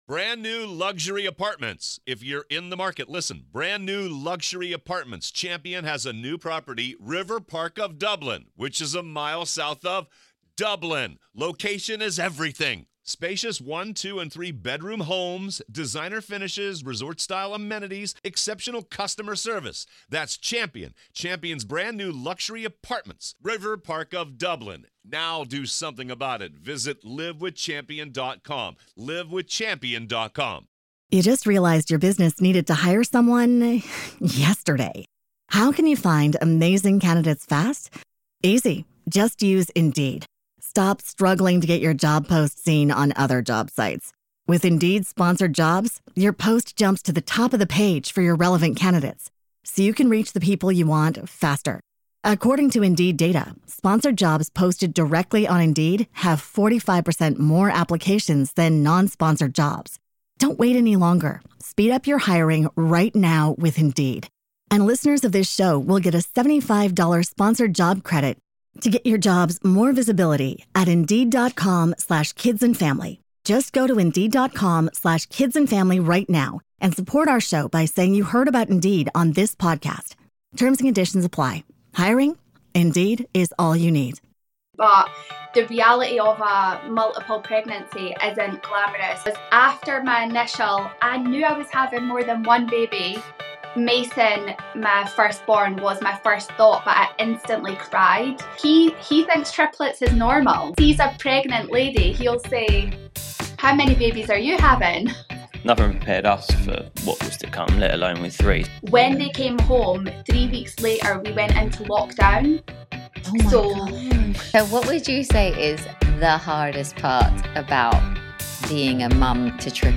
This is an honest, warm, and inspiring chat that pulls back the curtain on a parenting experience few truly understand, and you won’t want to miss it.